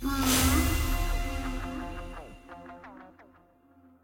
sfx-treasuretroves-reveal-epic.ogg